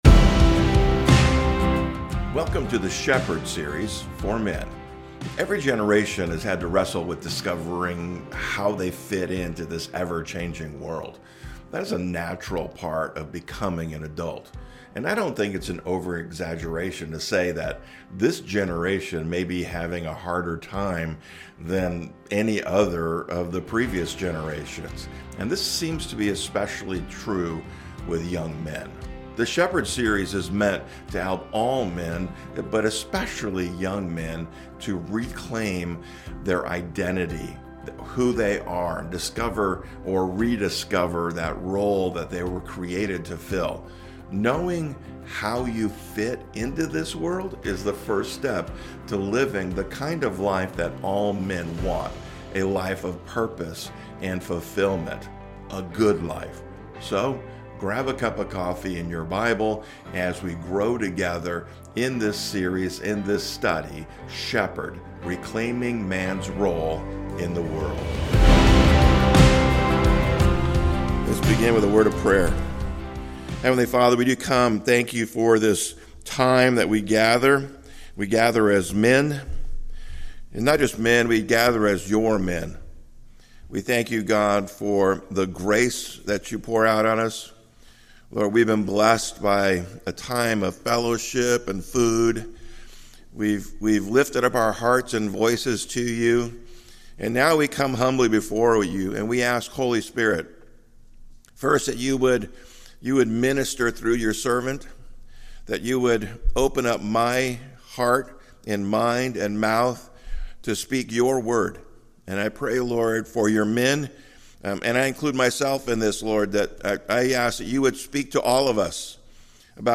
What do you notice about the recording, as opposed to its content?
STRENGTH TRAINING Men's Breakfast - Calvary Chapel French Valley